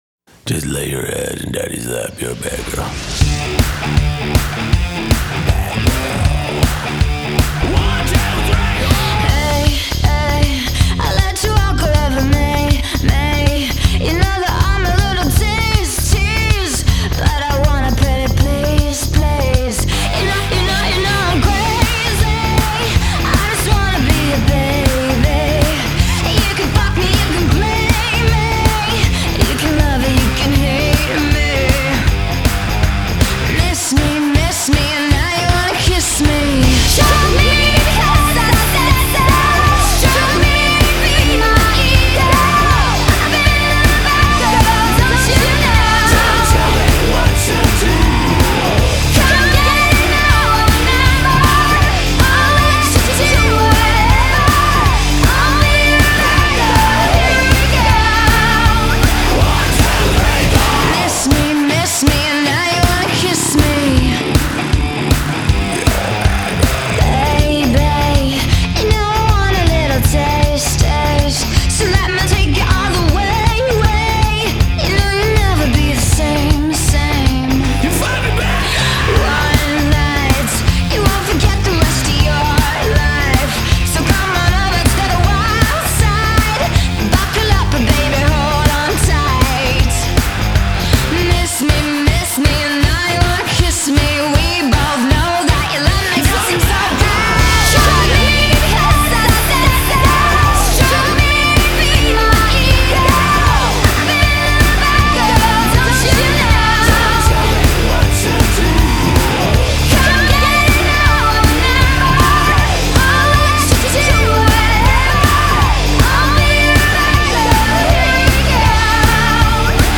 Назад в Rock
Жанры: Альтернативный рок, поп-панк, поп-рок, пост-гранж